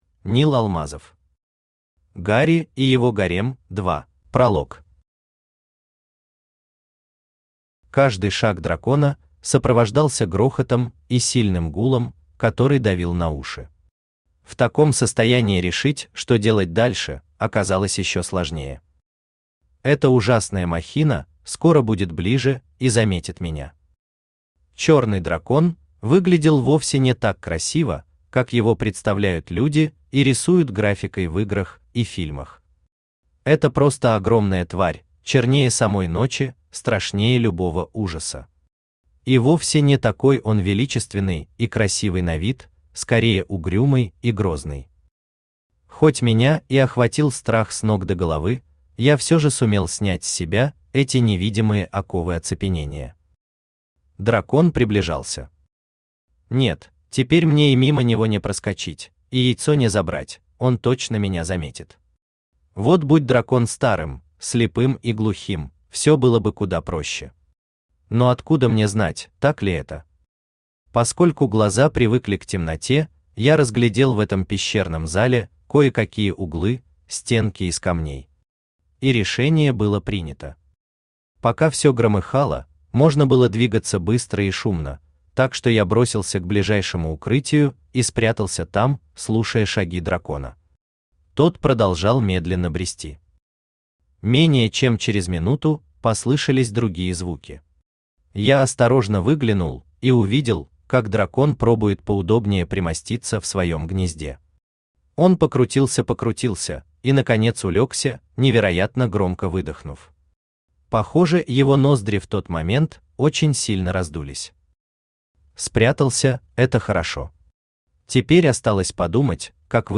Aудиокнига Гарри и его гарем – 2 Автор Нил Алмазов Читает аудиокнигу Авточтец ЛитРес. Прослушать и бесплатно скачать фрагмент аудиокниги